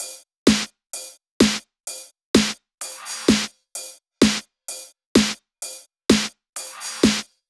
VFH2 128BPM Tron Quarter Kit 8.wav